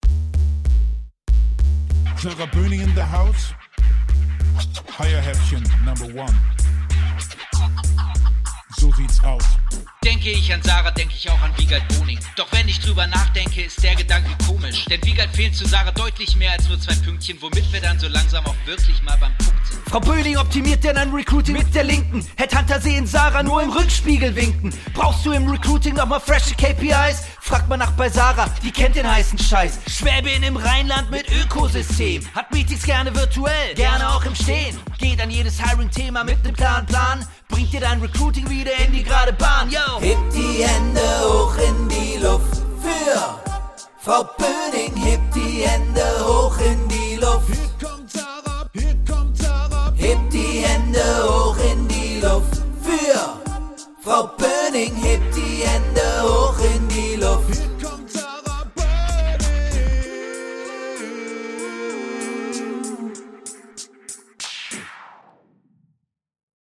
Der Recruiting Rap
Ein sehr individueller & humorvoller Song anlässlich des Hire Häppchen Events von Cammio.